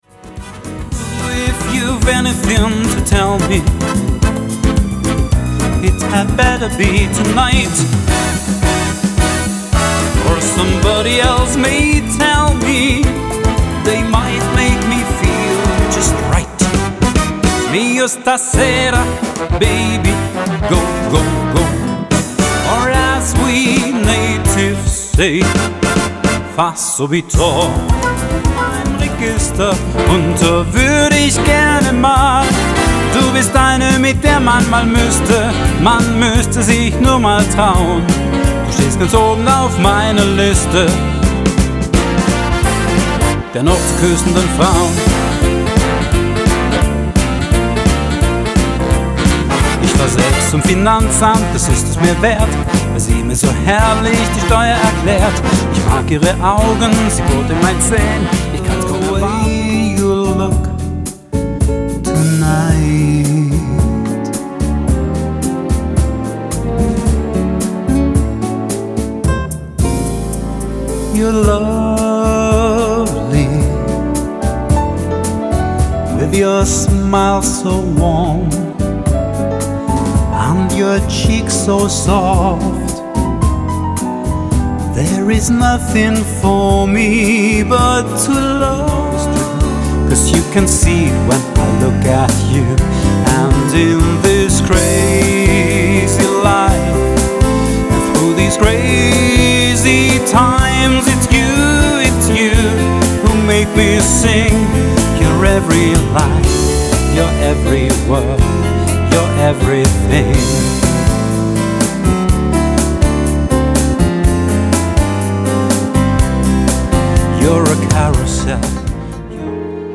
Gesang